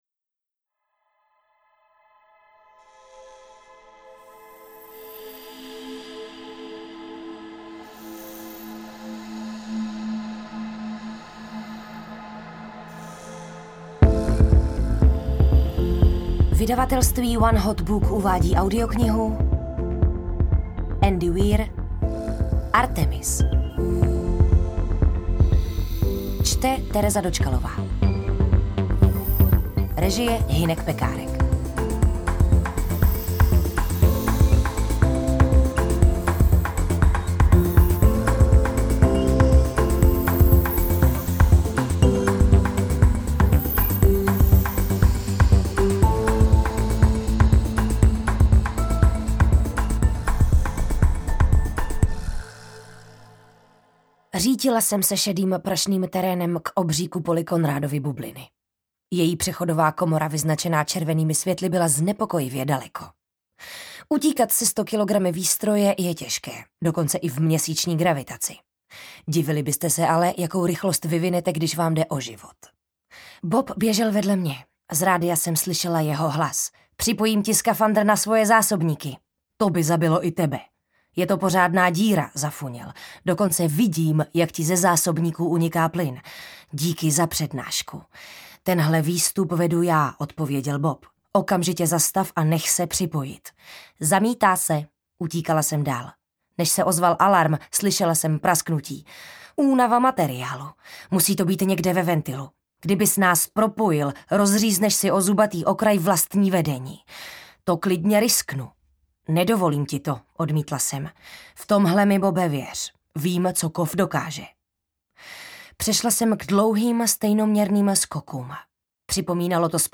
Interpreti:  Tereza Dočkalová, Marek Lambora
AudioKniha ke stažení, 27 x mp3, délka 9 hod. 23 min., velikost 637,0 MB, česky